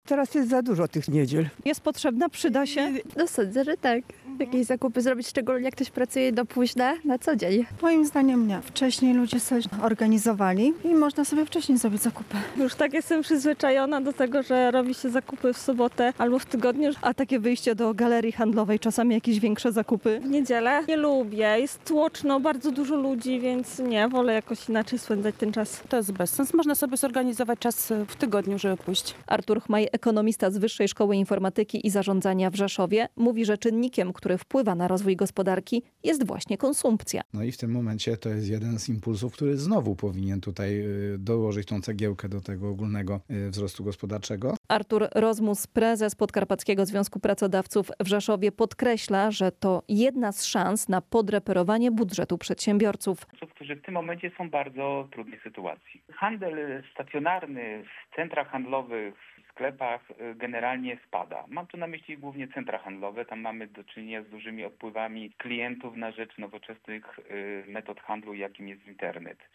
[SONDA] Dziś niedziela handlowa.